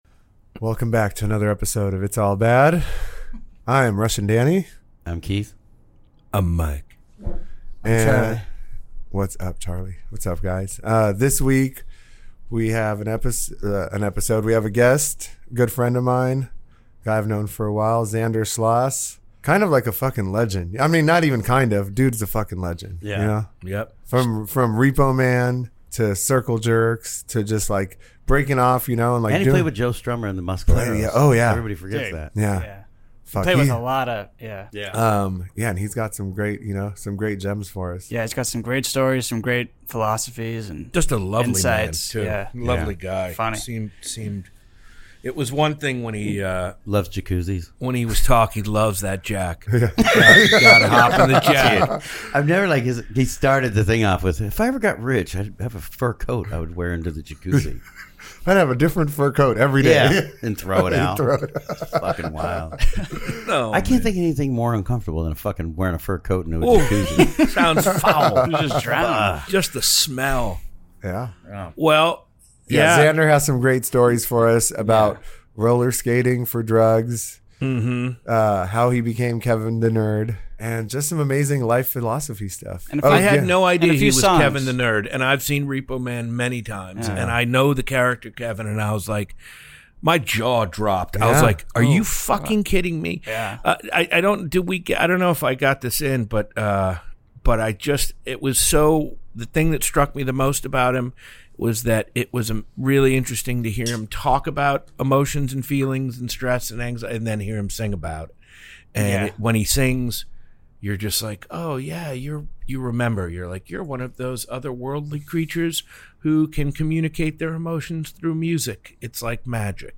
Either way, he's got some amazing stories for us and thoughts on addiction and the magic and tragedy of life. That and some solo acoustic songs to tie it all together.